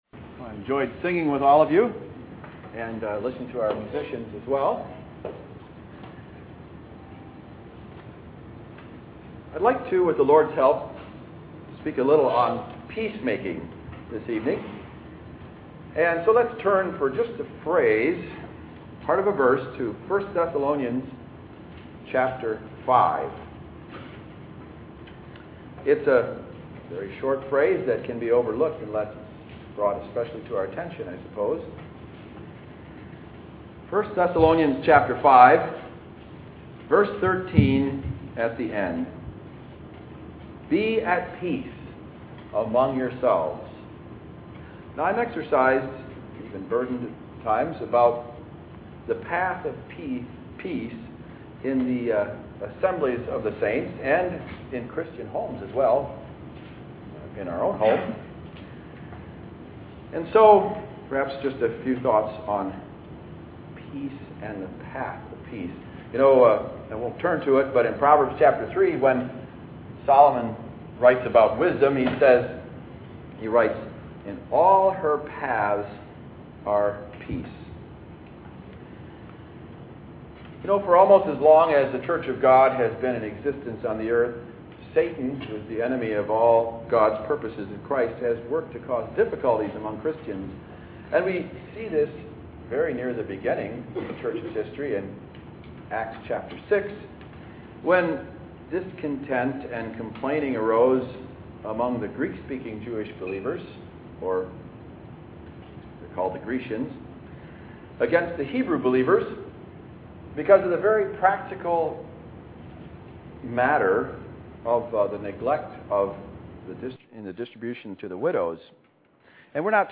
Posted below is an audio recording of remarks made to a group of Christian young people at a Bible conference in St Louis on the subject of maintaining sincere love and affection for each other, and on the very real need for peacemaking at home and in the assembly, beginning early in life.
stl2018yp1-sing-talk.mp3